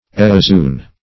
Search Result for " eozoon" : The Collaborative International Dictionary of English v.0.48: Eozoon \E`o*zo"["o]n\, n.; pl.